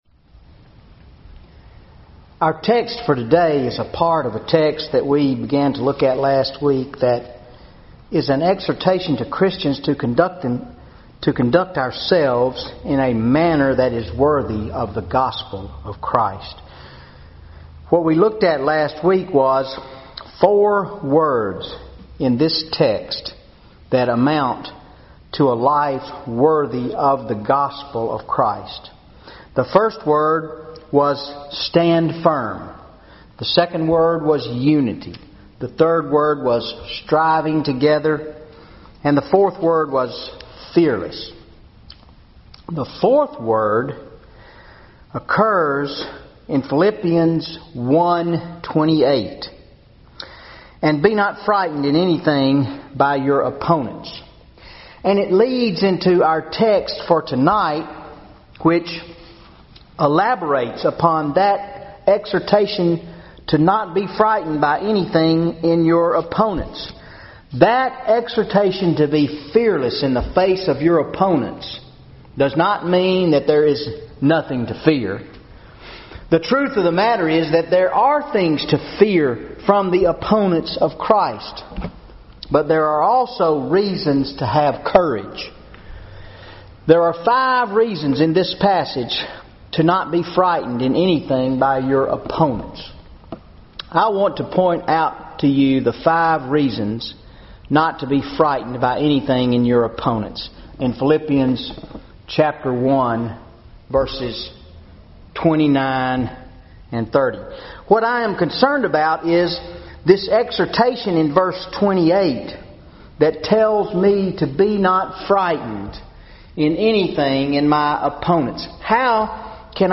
Wednesday Night Bible Study September 18,2013 Philippians 1:28-30 How Can I Face Opposition and Adversity without Fear?